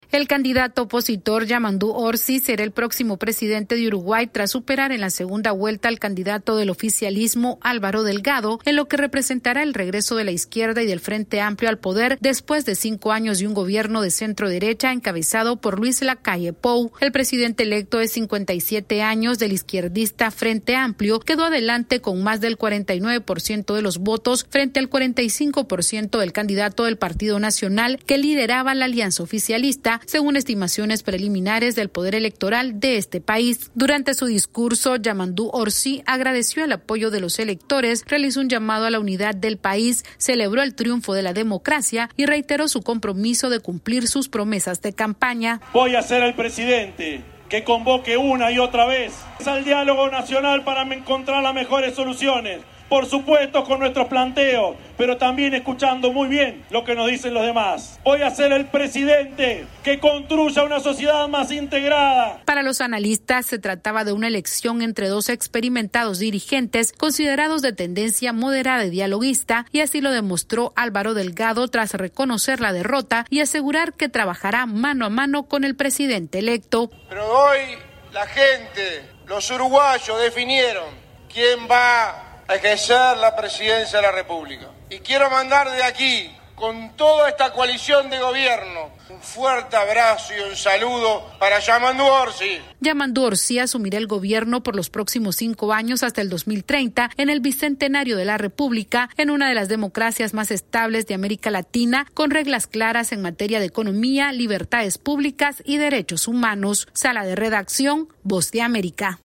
AudioNoticias
Esta es una actualización de nuestra Sala de Redacción.